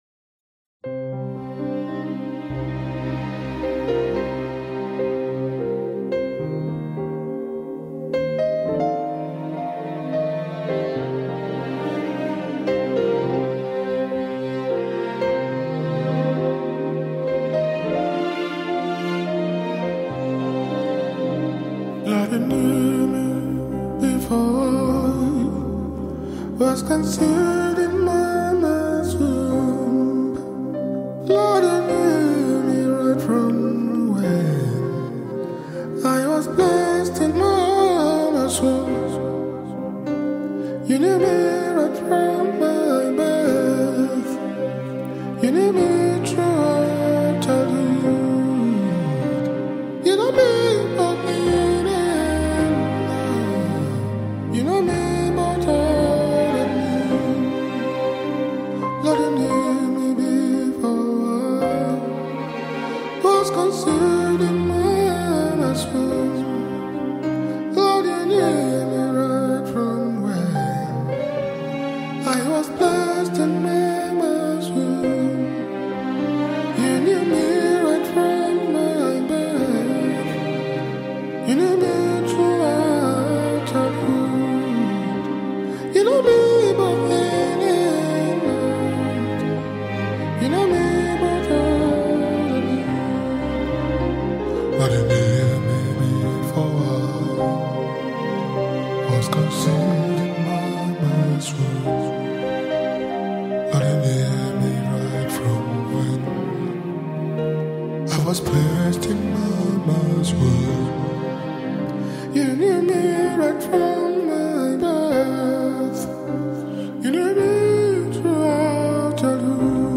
Powerful song